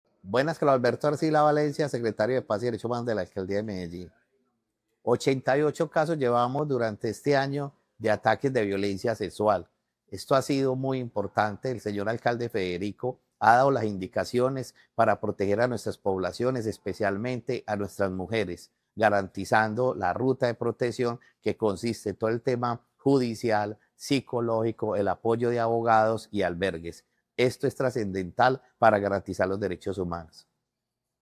Declaraciones-secretario-de-Paz-y-Derechos-Humanos-Carlos-Alberto-Arcila-Valencia.mp3